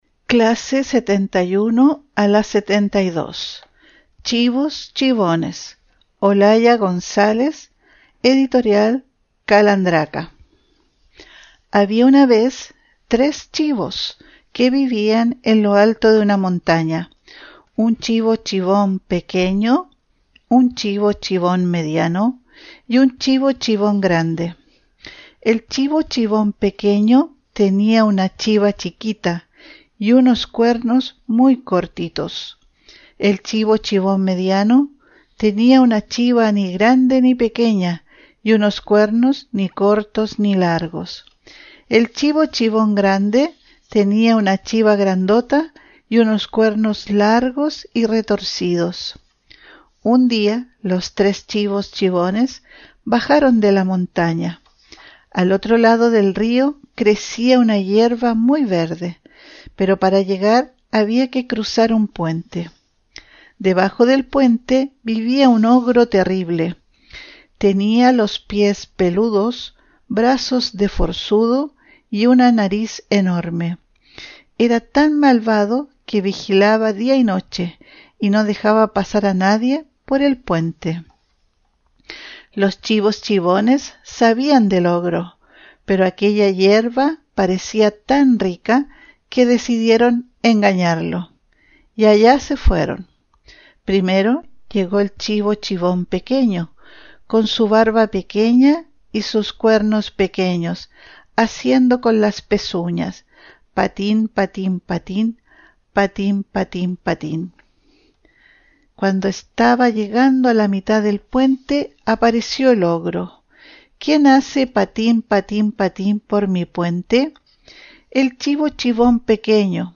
Audiolibro: Chivos chivones
Tipo: Audiolibros